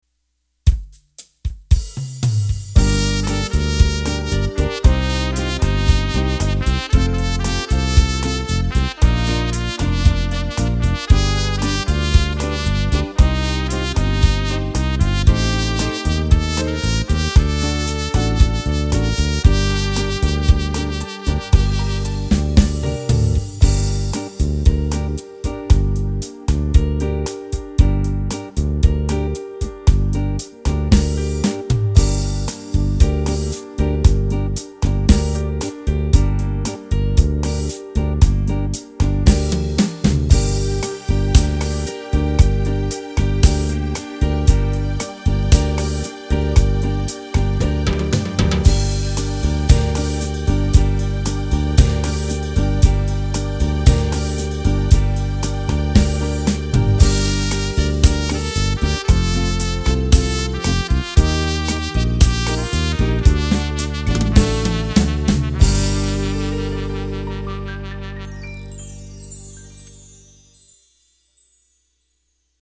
(Gm) Bossanova - Female